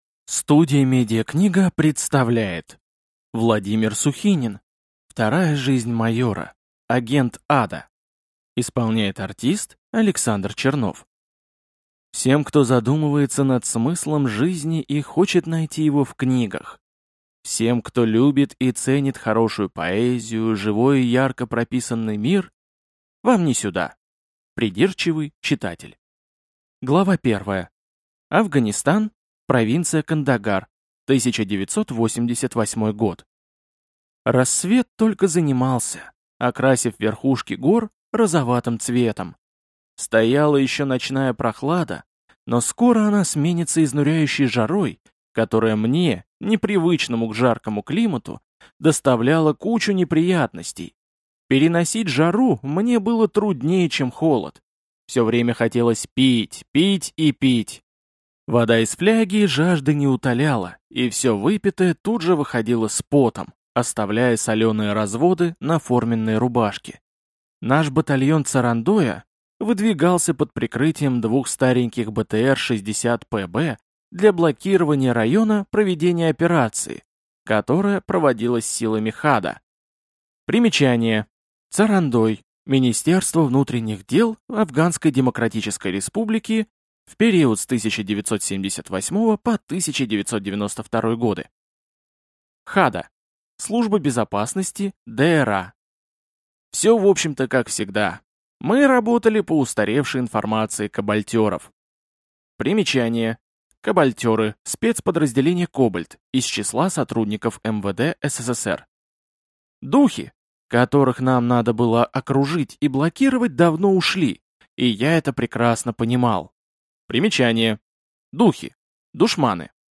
Аудиокнига Вторая жизнь майора. Агент ада | Библиотека аудиокниг